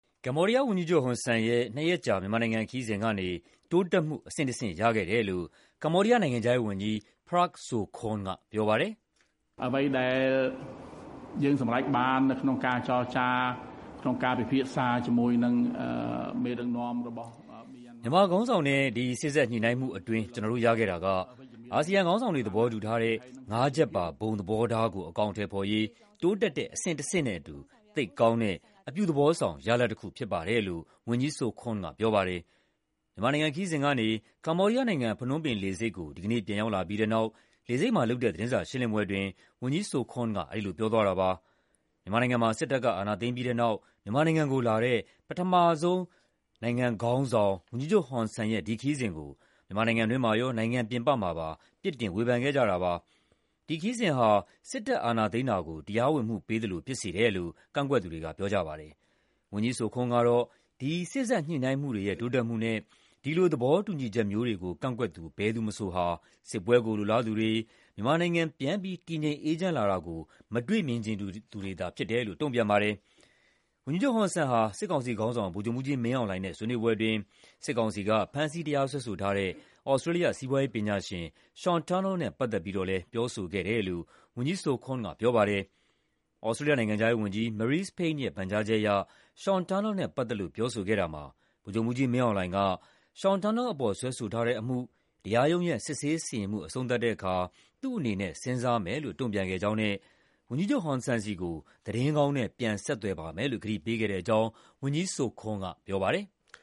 မြန်မာနိုင်ငံ ခရီးစဉ်ကနေ ကမ္ဘောဒီးယားနိုင်ငံ၊ ဖနွမ်းပင်လေဆိပ်ကို ဒီကနေ့ ပြန်ရောက်လာပြီးတဲ့နောက် လေဆိပ်မှာလုပ်တဲ့ သတင်းစာရှင်းလင်းပွဲအတွင်း ဝန်ကြီး Sokhonn က အဲဒီလို ပြောသွားတာပါ။